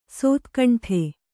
♪ sōtkaṇṭhe